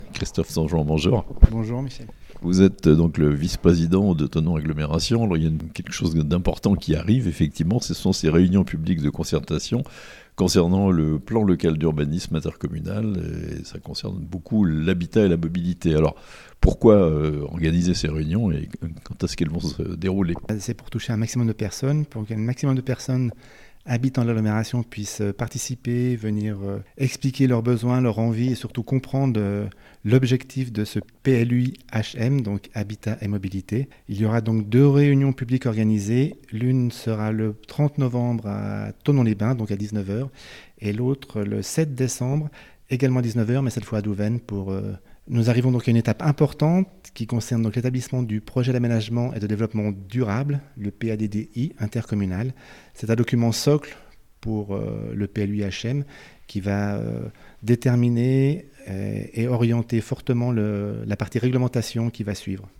Thonon Agglomération : des réunions publiques autour du Projet d’aménagement et de développement durables (interview)
Christophe Songeon, premier vice-président de Thonon Agglomération.